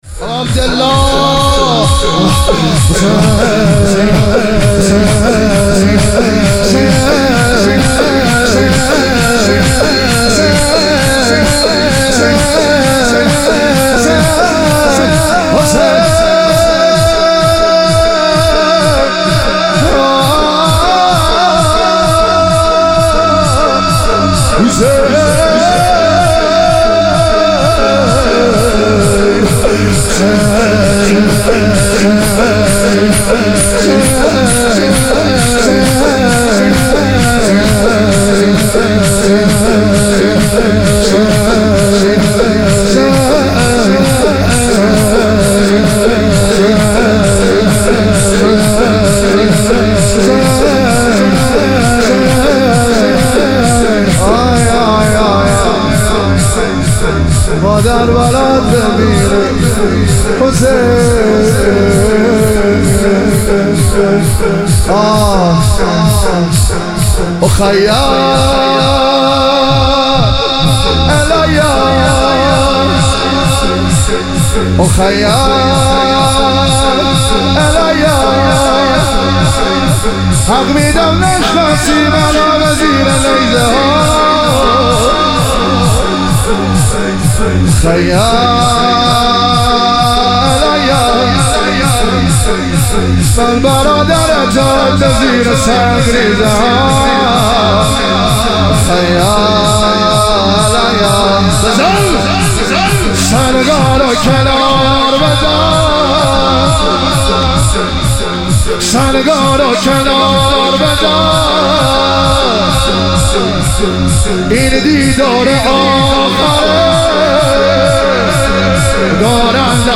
مشهد الرضا - لطمه زنی